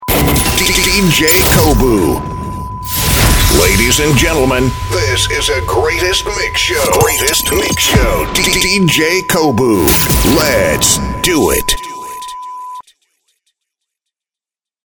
黒人の渋いボイスが引き立つ仕上がりになりました。